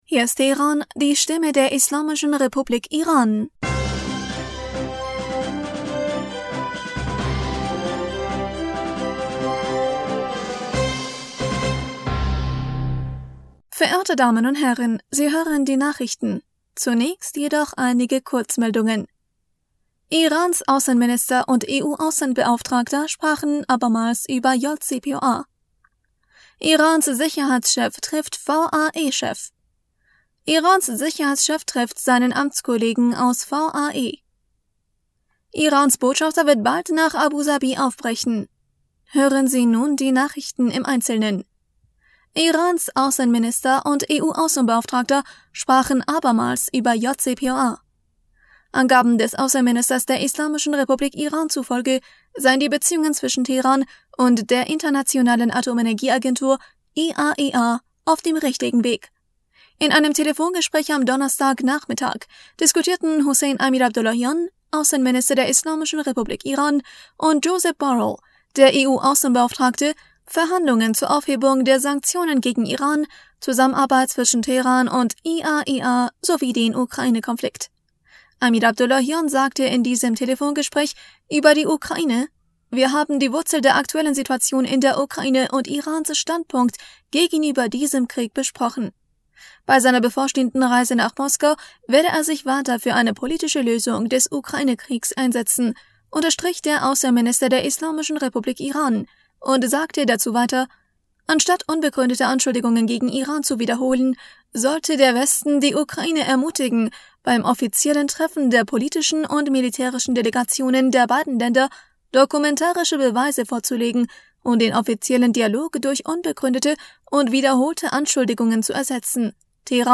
Nachrichten vom 17. März 2023